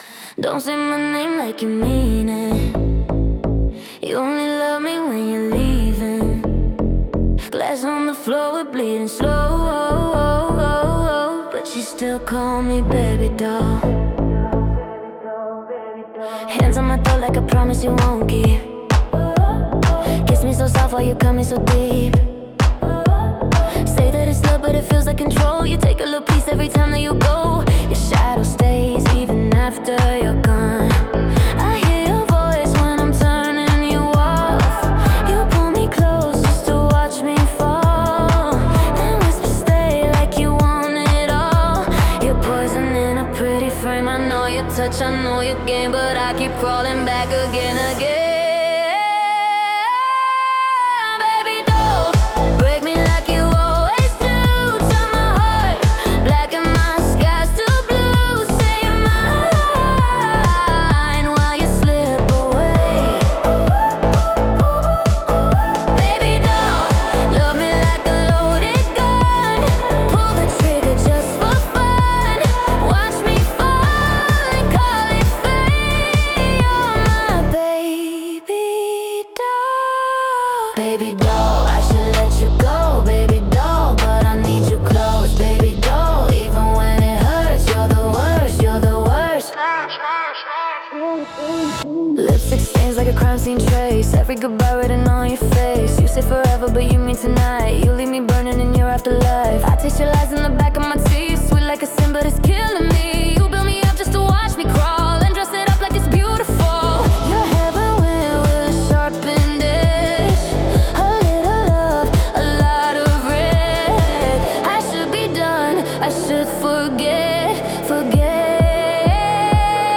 Pop 2026 Non-Explicit